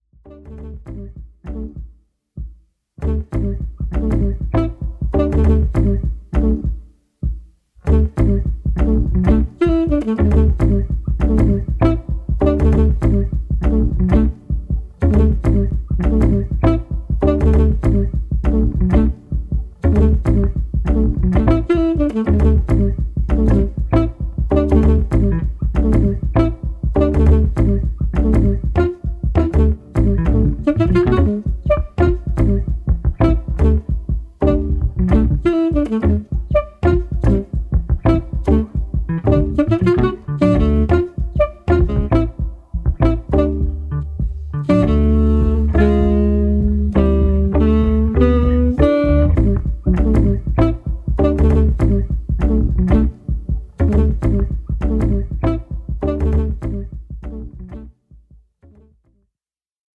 ホーム ｜ JAZZ